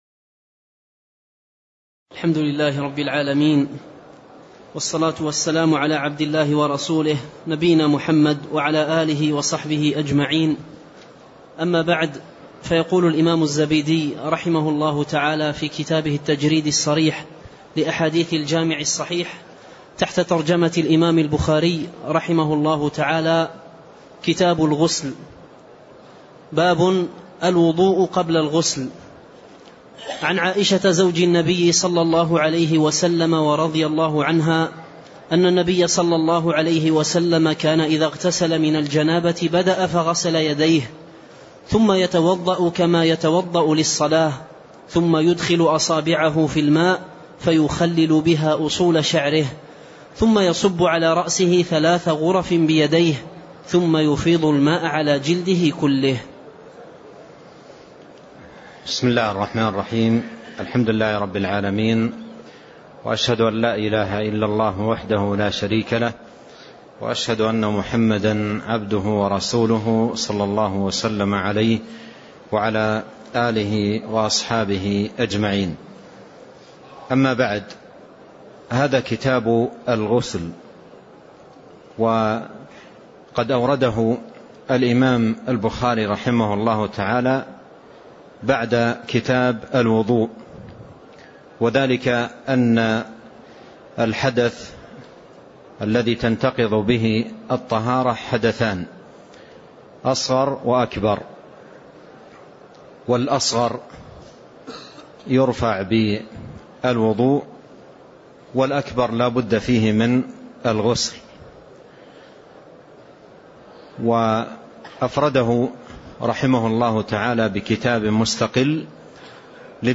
تاريخ النشر ١ جمادى الآخرة ١٤٣٣ هـ المكان: المسجد النبوي الشيخ